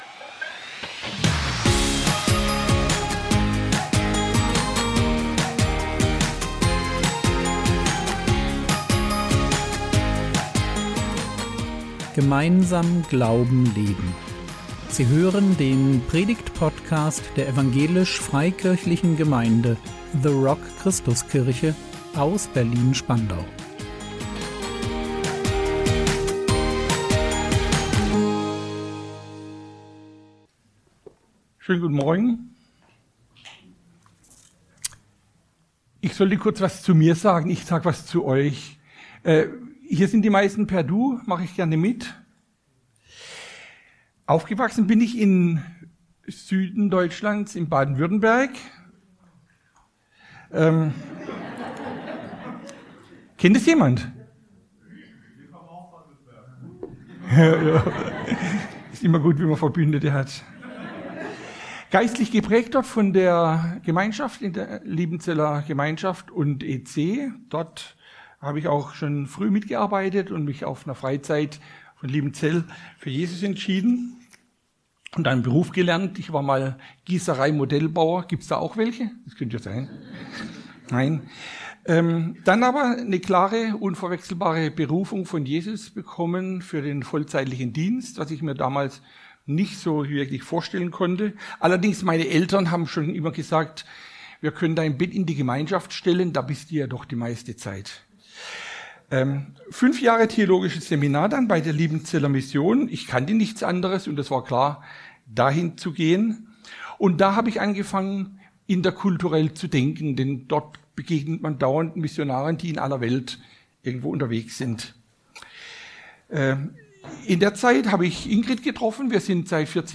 Echten Frieden finden | 02.02.2025 ~ Predigt Podcast der EFG The Rock Christuskirche Berlin Podcast